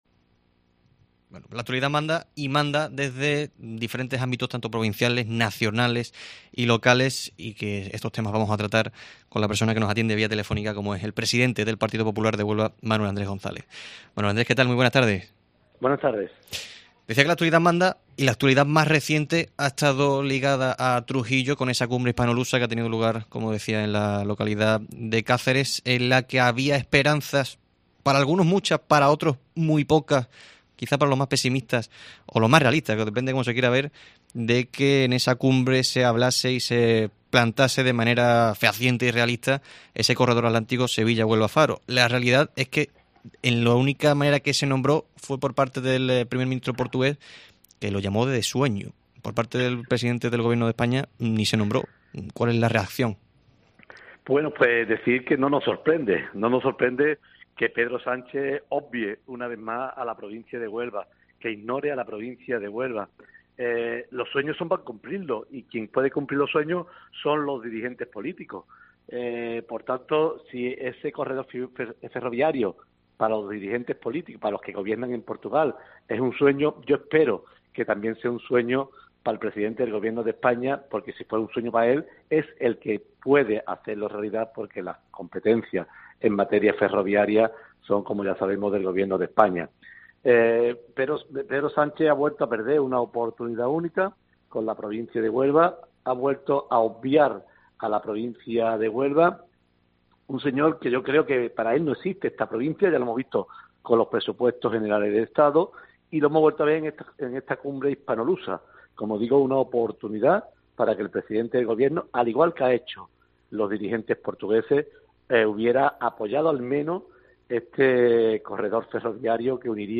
El presidente del PP Huelva, Manuel Andrés González, ha atendido a COPE Huelva en una entrevista donde ha analizado las últimas noticias relacionadas...
Entrevista a Manuel Andrés González, presidente del PP Huelva